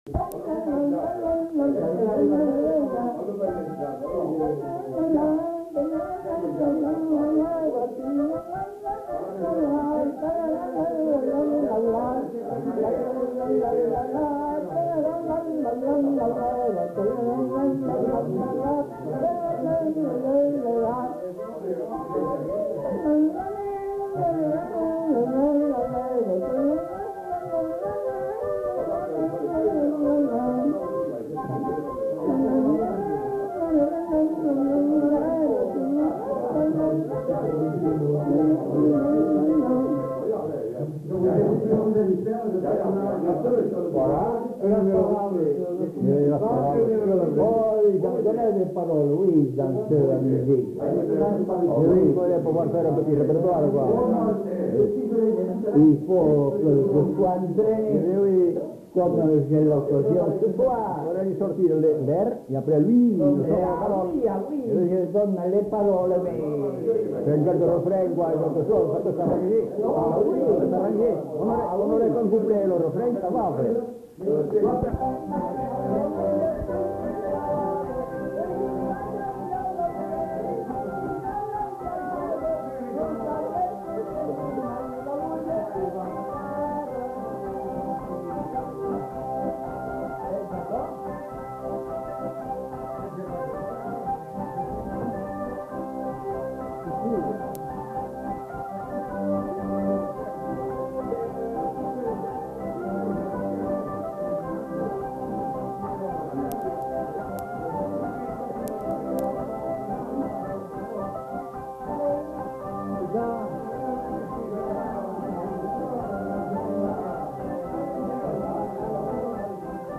Lieu : Estigarde
Genre : morceau instrumental
Instrument de musique : harmonica
Danse : rondeau
Notes consultables : Fredonné par un monsieur non identifié. Contient une conversation à propos du rondeau.